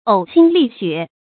注音：ㄡˇ ㄒㄧㄣ ㄌㄧˋ ㄒㄩㄝˋ
嘔心瀝血的讀法